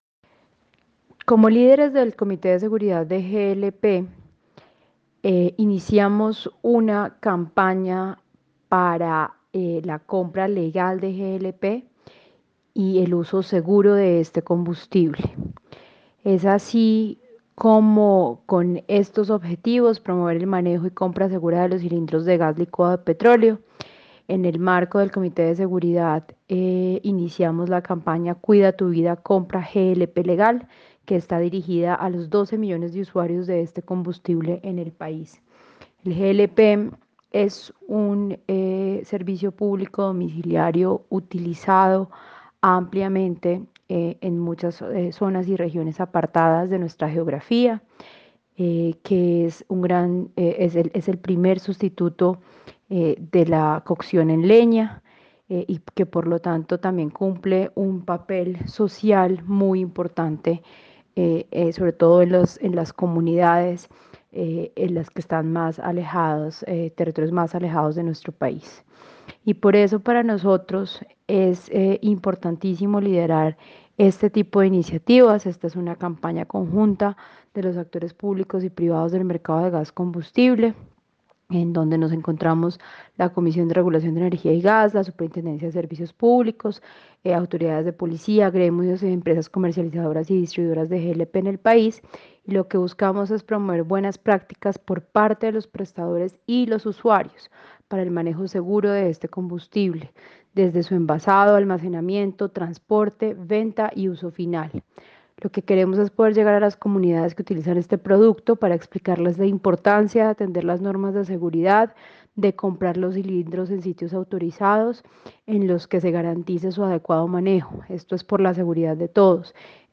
Declaraciones de la superintendente Natasha Avendaño García